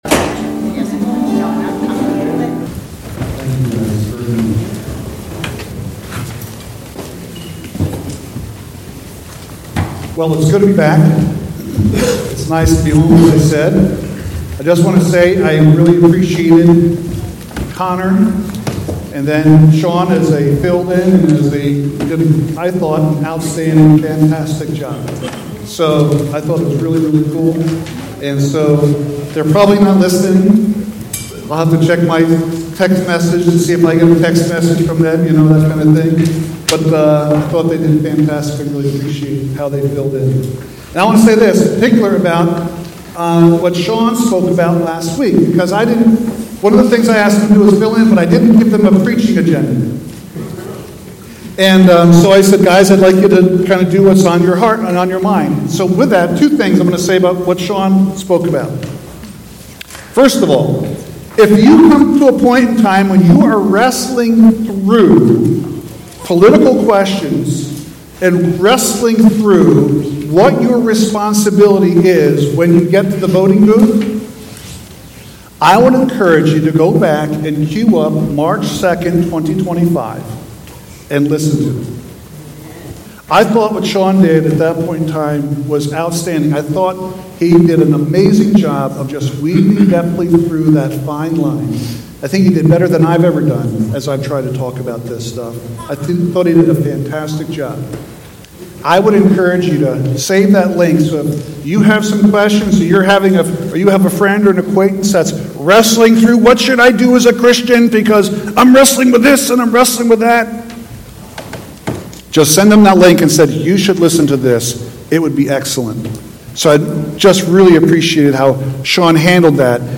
Sunday Service.